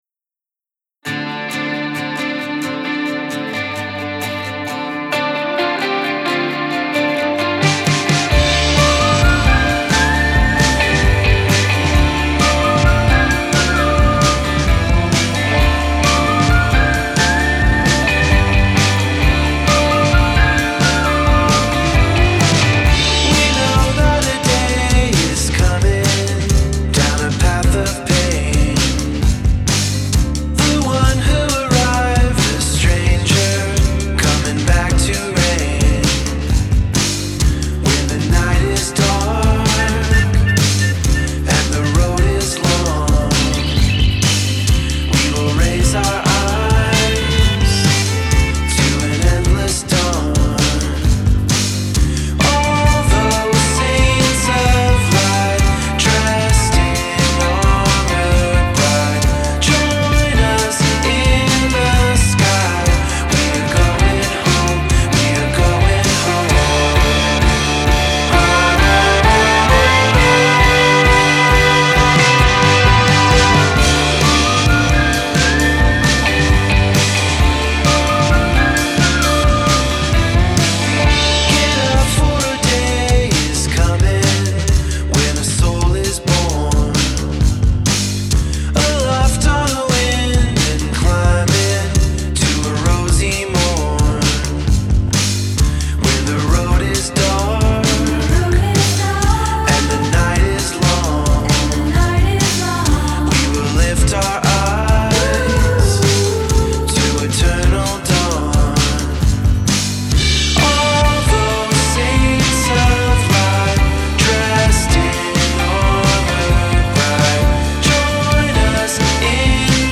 Genre: Alternative, Indie Rock